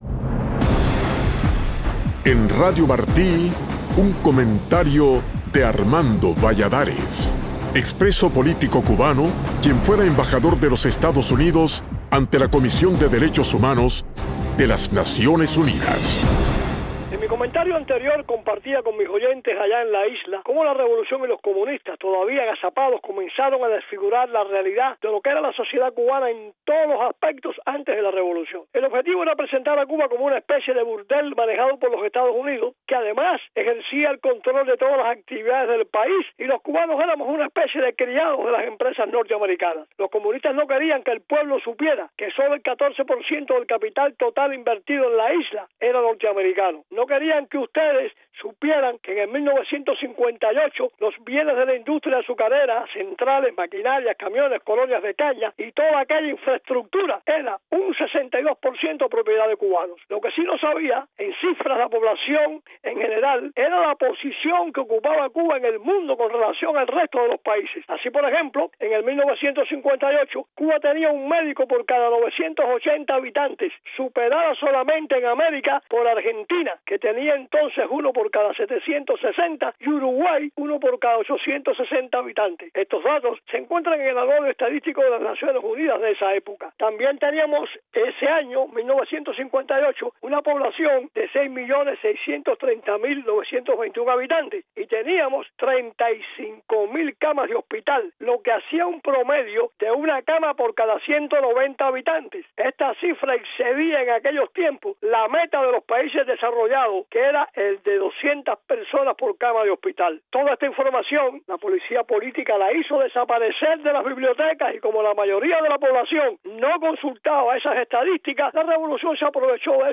Cuando los comunistas llegaron al poder quisieron borrar el pasado para hacer creer al pueblo que la isla siempre estuvo peor bajo el capitalismo, pero lo cierto es que el país gozaba de una prosperidad económica que no ha vuelto a tener en seis décadas. Así lo explica el embajador Valladares en su comentario de hoy.